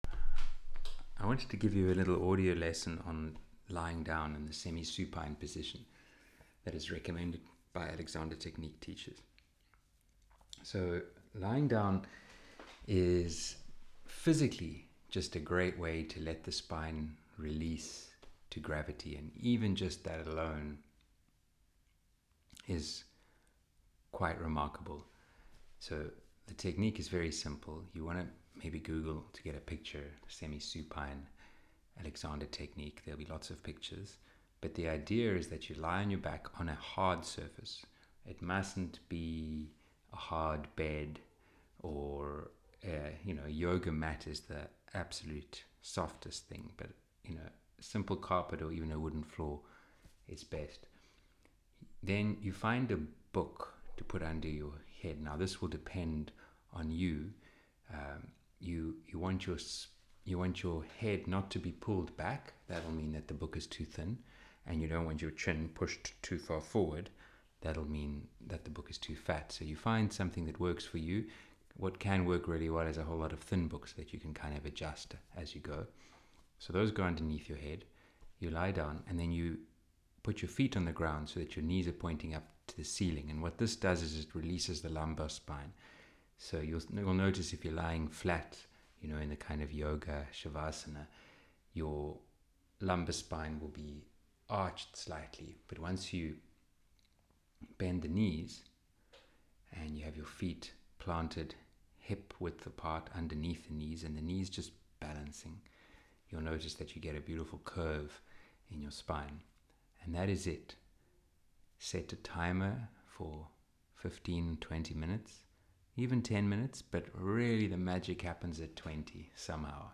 A twenty minute talk-through on lying down semi-supine, thinking, inhibition, and the basis of my approach to playing guitar.